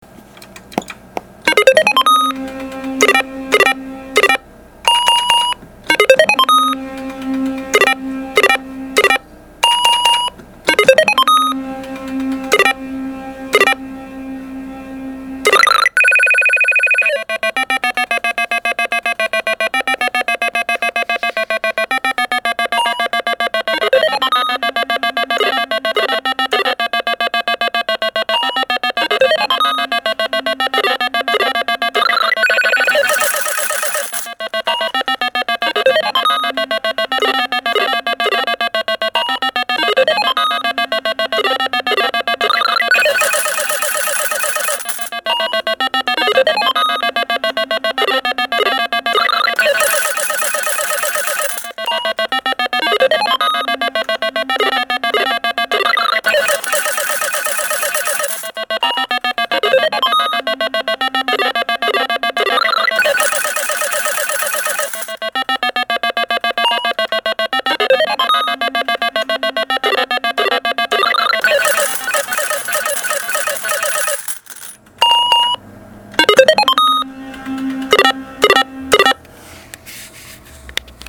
パチスロ実機
リスキーダック-ＲＥＧ音 ♪
risuki-dakku-reg.mp3